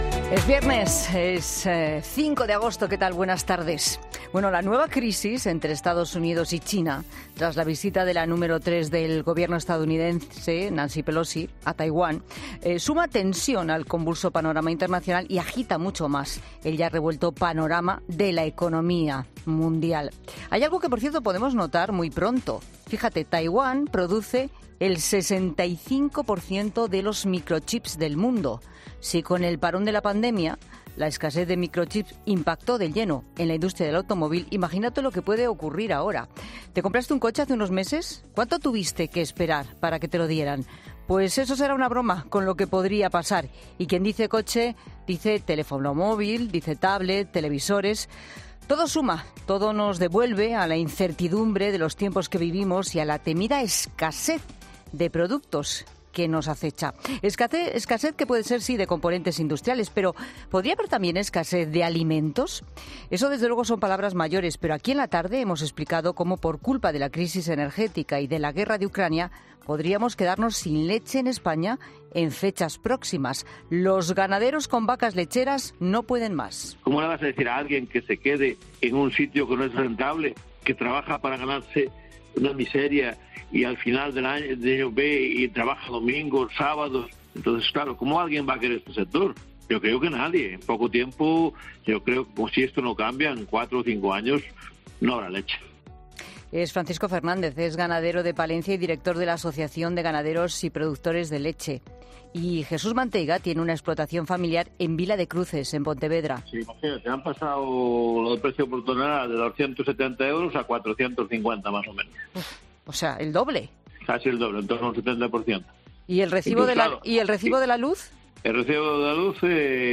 Monólogo de Pilar Cisneros